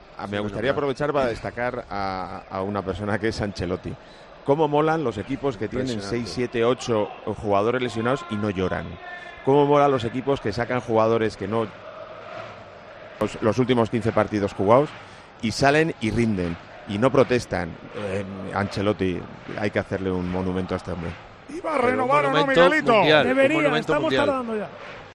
El ex futbolista del Real Madrid elogió a Carlo Ancelotti durante la retransmisión del Real Madrid - Nápoles y por varios motivos que explicó de forma sencilla en Tiempo de Juego.